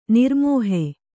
♪ nirmōhe